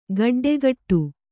♪ gaḍḍegaṭṭu